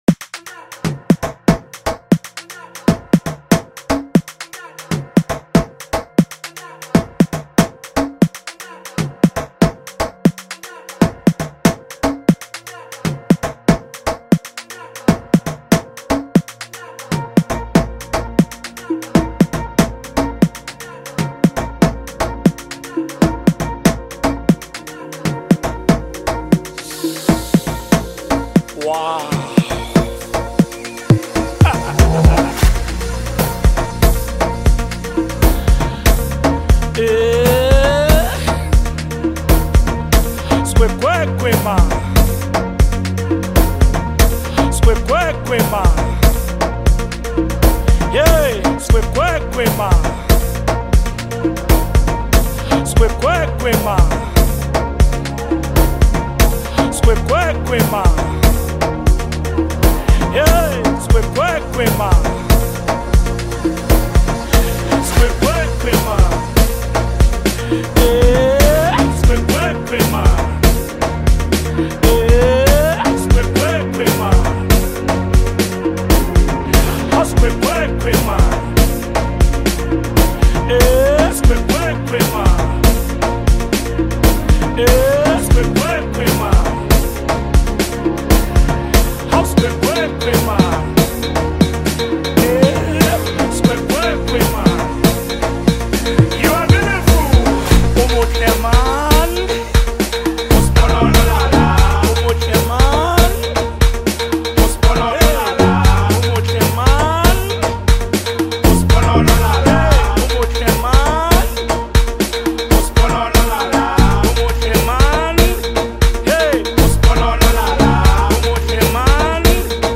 Amapiano, Gqom, Lekompo